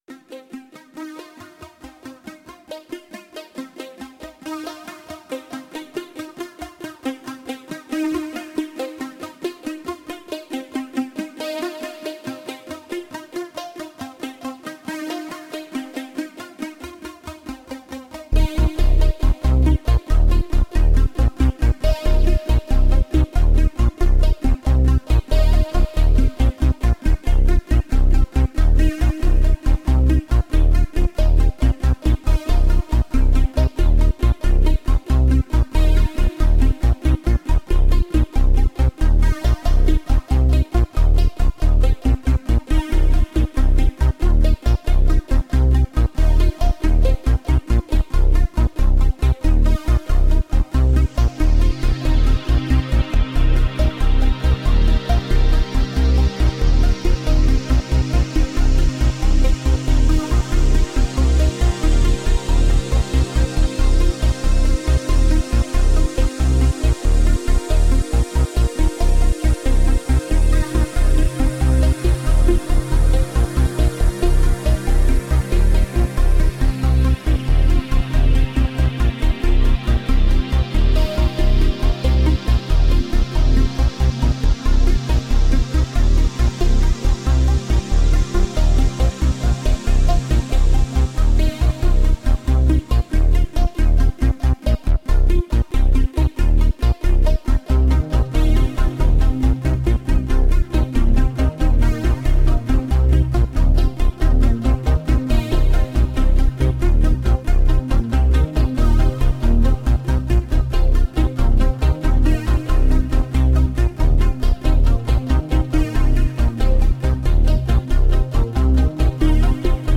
Huge ambient compositions..
Tagged as: Ambient, New Age, Space Music